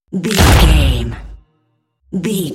Dramatic hit drum bone break
Sound Effects
Atonal
heavy
intense
dark
aggressive